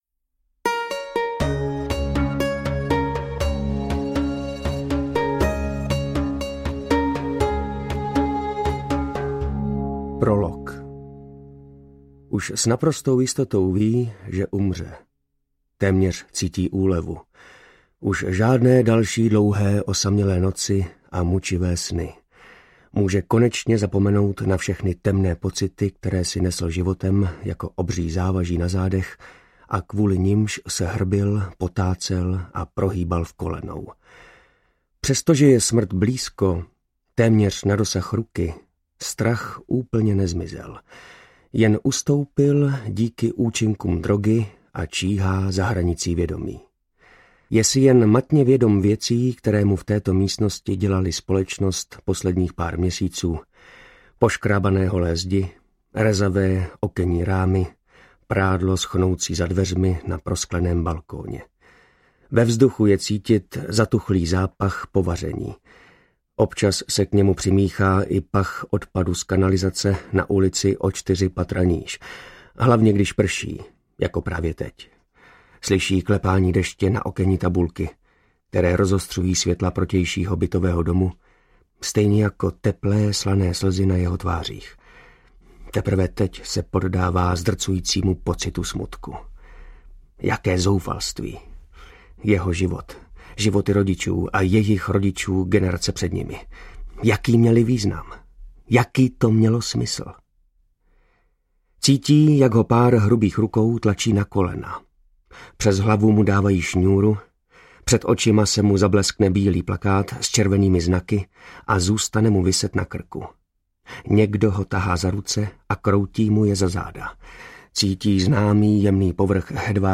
Čtvrtá oběť audiokniha
Ukázka z knihy
• InterpretMartin Myšička, Jana Plodková